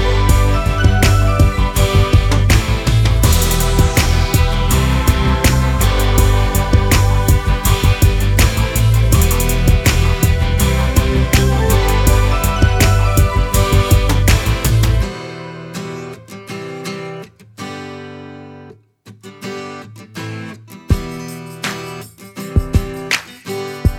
no Backing Vocals Rock 3:36 Buy £1.50